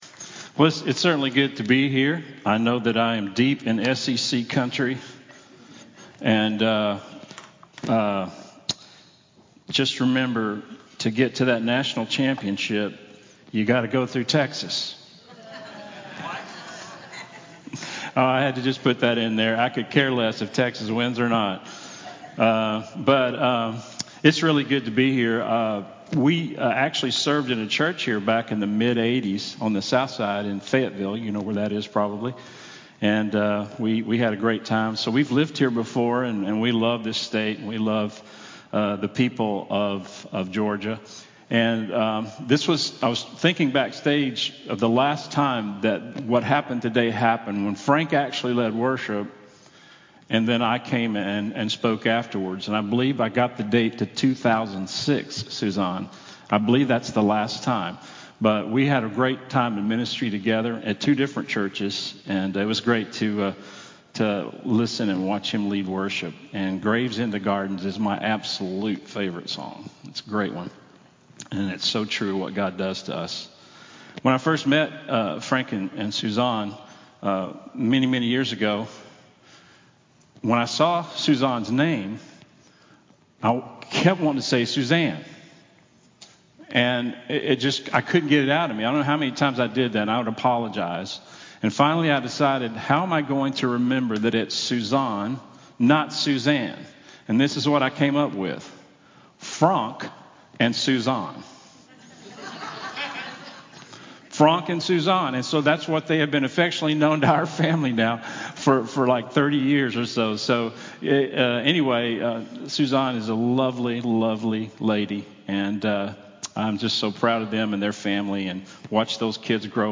Guest Speaker
Fasten-Your-Seltbelts-Sermon-Audio-CD.mp3